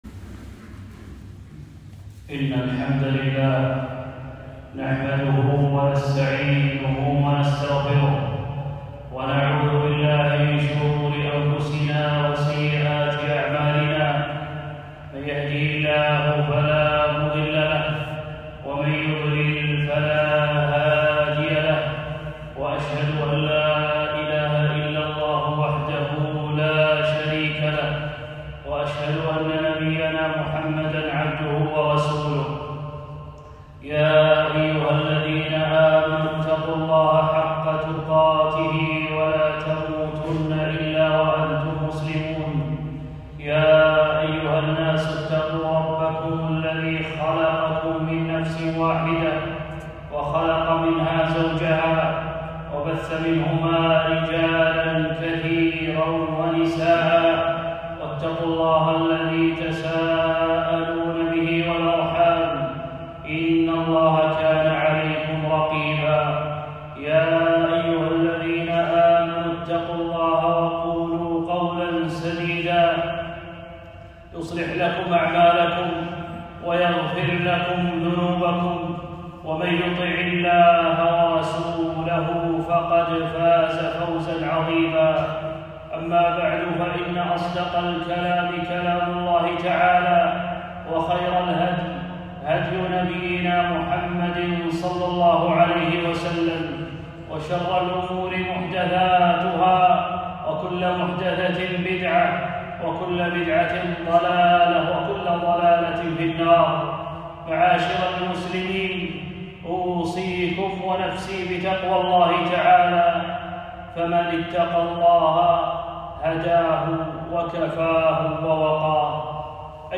خطبة - نصرة النبي صلى الله عليه وسلم.